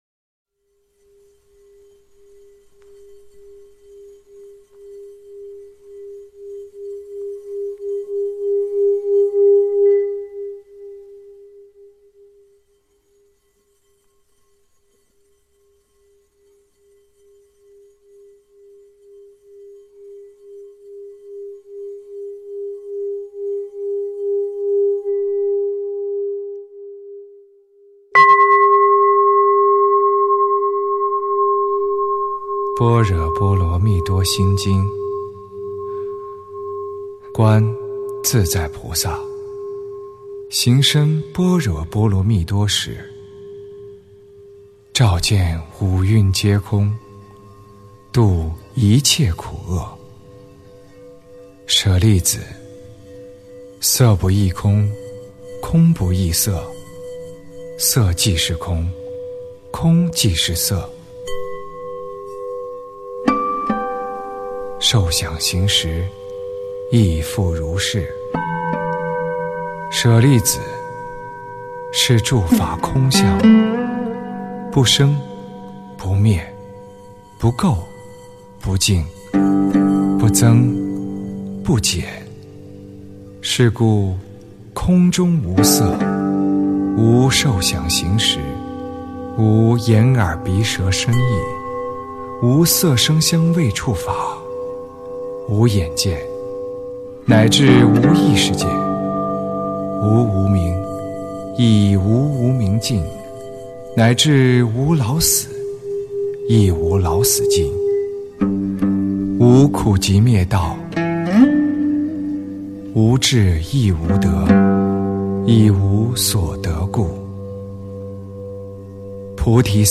般若波罗蜜多心经（念诵) 诵经 般若波罗蜜多心经（念诵)--黄晓明 点我： 标签: 佛音 诵经 佛教音乐 返回列表 上一篇： 心经 下一篇： 心经 相关文章 佛子行三十七颂--慈囊仁波切 佛子行三十七颂--慈囊仁波切...